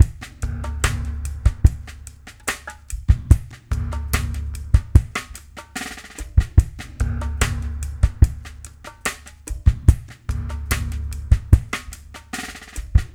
BOL LOFIMX-L.wav